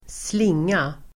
Uttal: [²sl'ing:a]